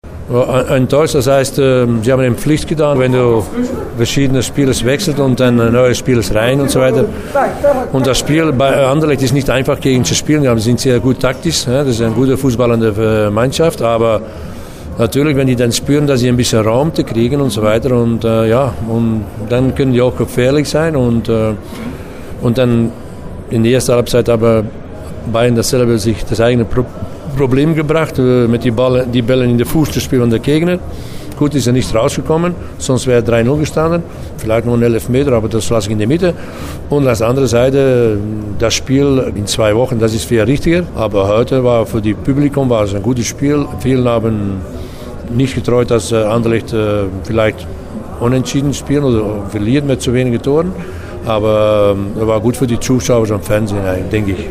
Auf die Frage, ob ihn der Auftritt des FC Bayern in Brüssel enttäuscht habe, antwortete Pfaff: